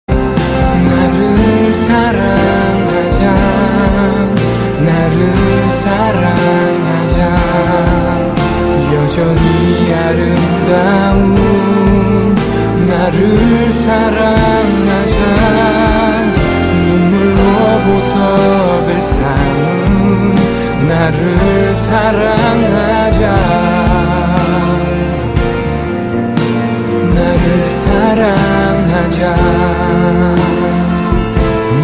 M4R铃声, MP3铃声, 日韩歌曲 126 首发日期：2018-05-15 12:54 星期二